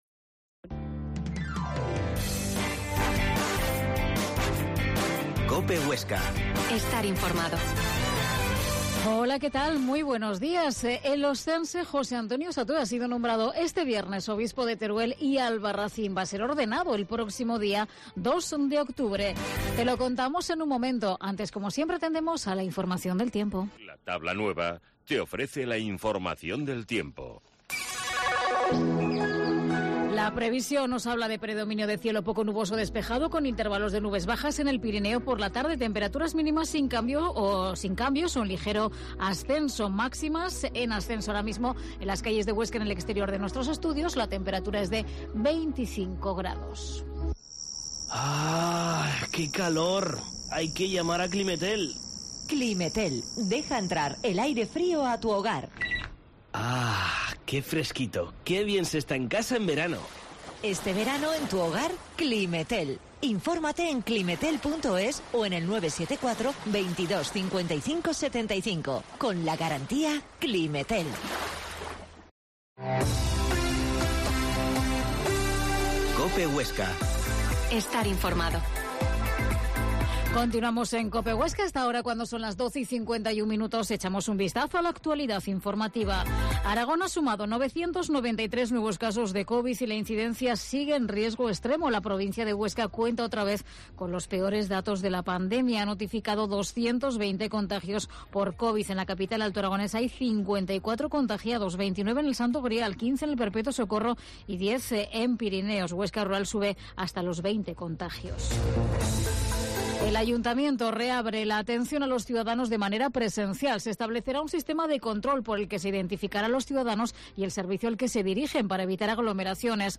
Herrera en COPE Huesca 12.50h. Entrevista al nuevo obispo de Teruel y Albarracín, Don José Antonio Satué